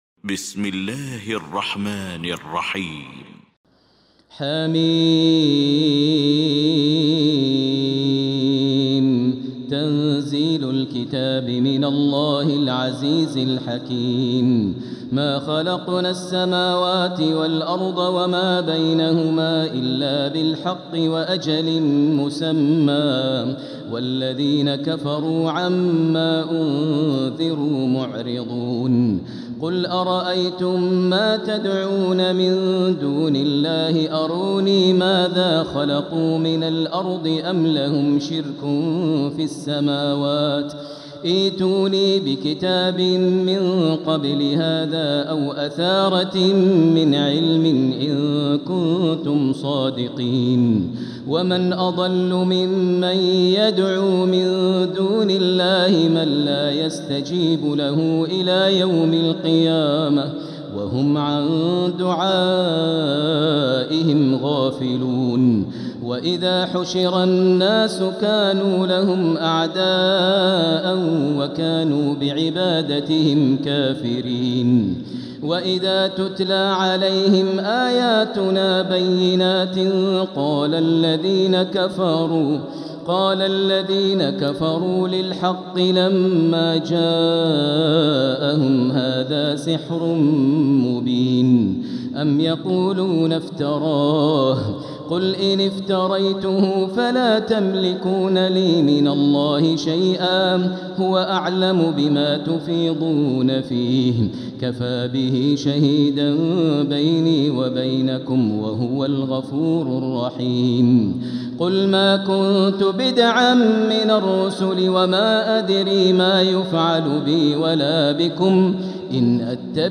المكان: المسجد الحرام الشيخ: فضيلة الشيخ ماهر المعيقلي فضيلة الشيخ ماهر المعيقلي الأحقاف